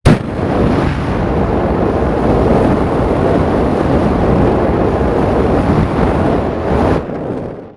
flamethrower_cast.mp3